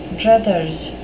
druthers (DRUTH-uhrz) noun
Pronunciation: